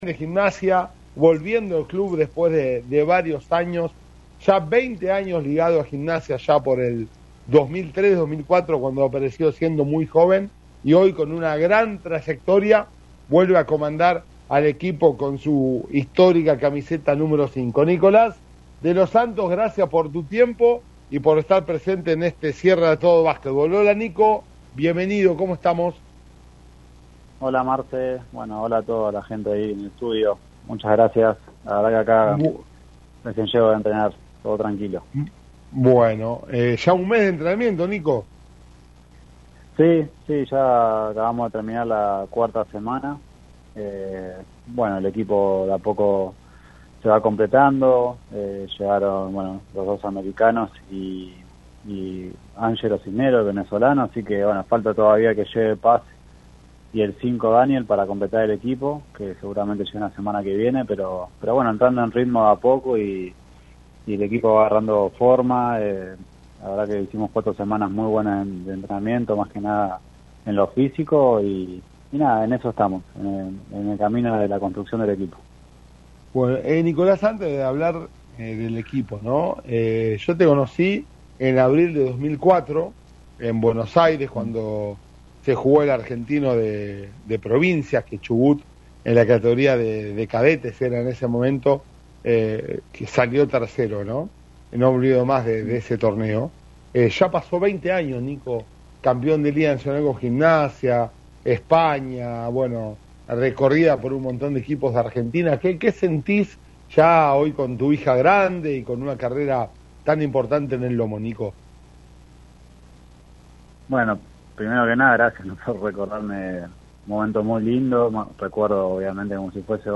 En el medio de la pretemporada, el experimentado base dialogó en “A Todo Básquetbol” por LaCienPuntoUno sobre la decisión de volver y lo que vienen siendo los primeros entrenamientos”.